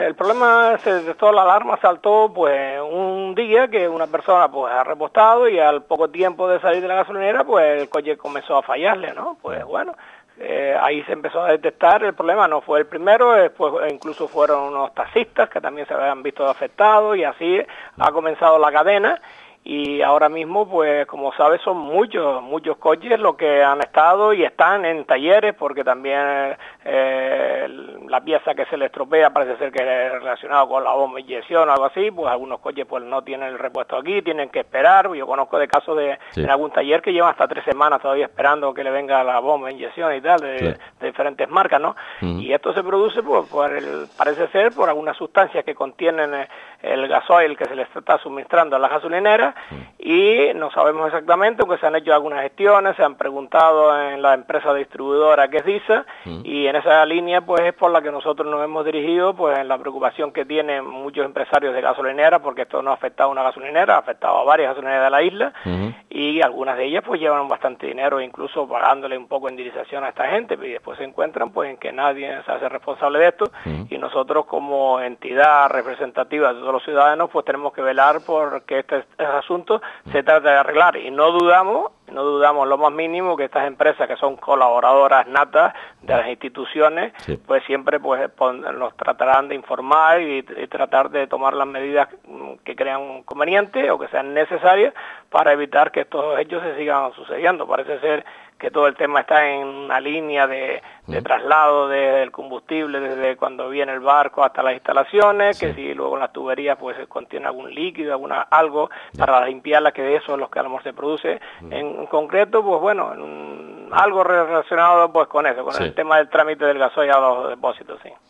Así lo ha desvelado este martes el consejero de Industria y Consumo del Cabildo, Emilio Bermúdez Hernández, en declaraciones realizadas a los servicios informativos de Lancelot Radio Onda Cero, después de que dicha corporación emitiera un comunicado de prensa informando que ha solicitado a Disa que analice el gasoil que distribuye en la isla por su supuesto “mal estado”.